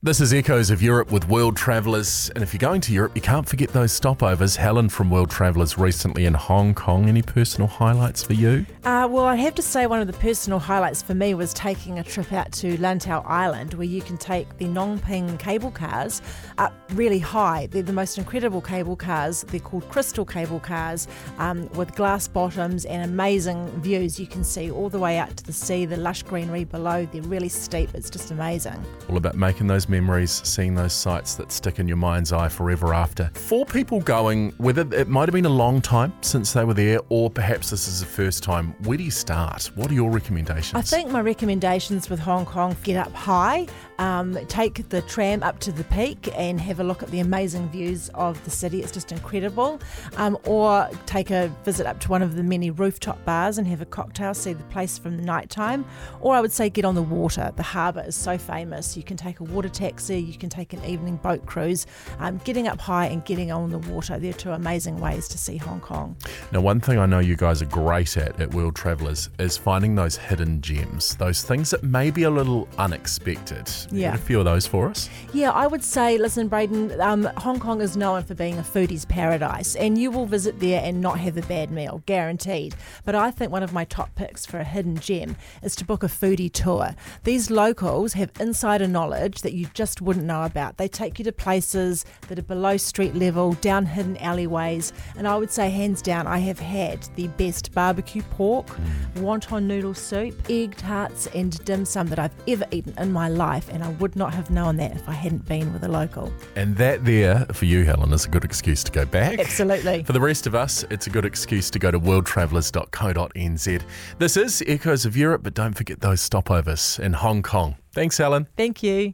Listen to an audio snippet from a recent traveller: